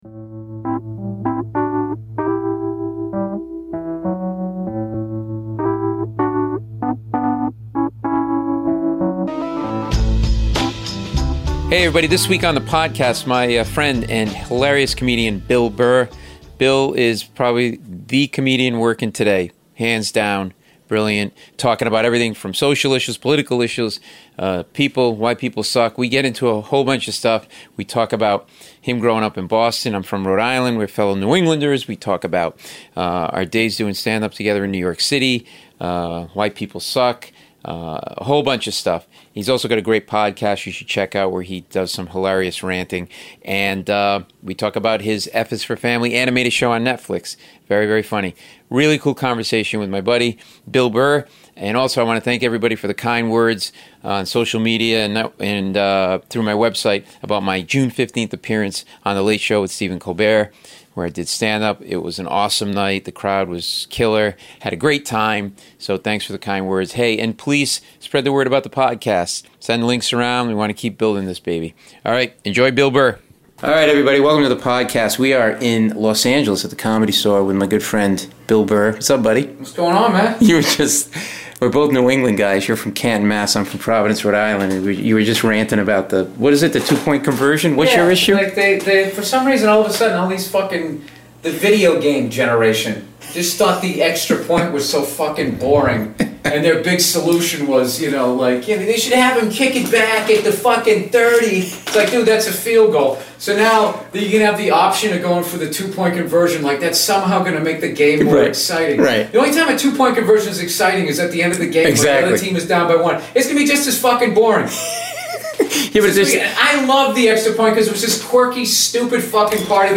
Bill Burr (Paul Mecurio interviews Bill Burr; 22 Jun 2016) | Padverb
Comedian Bill Burr.